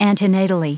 Prononciation du mot antenatally en anglais (fichier audio)
Prononciation du mot : antenatally